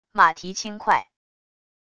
马蹄轻快wav音频